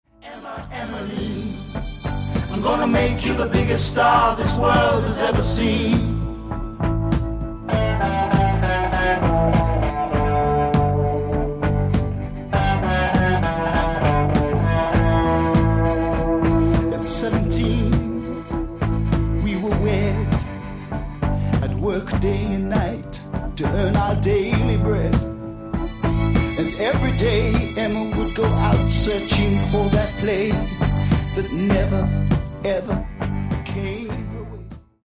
Genere:   Disco | Funk | Soul